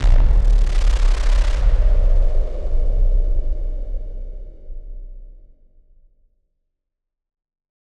BF_SynthBomb_D-01.wav